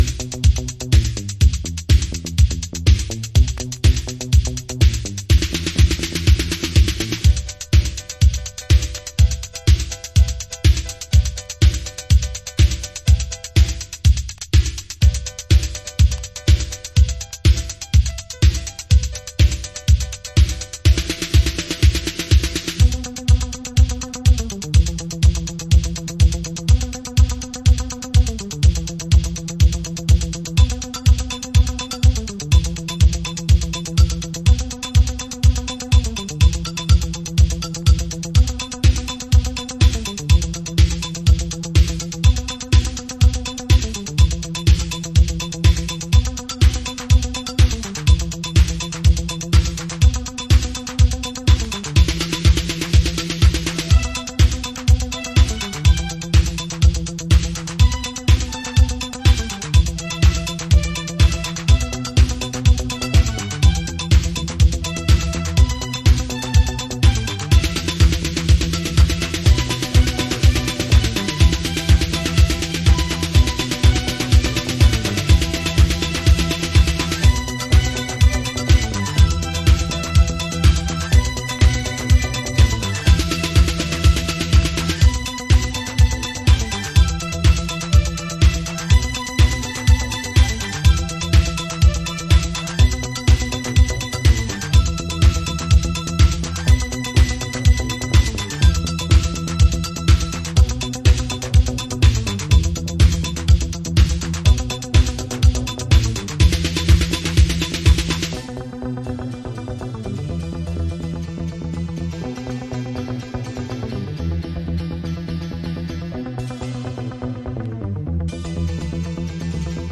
Chicago Oldschool / CDH
まだまだ、イタロや80'sディスコの影響が垣間見られるトラックス。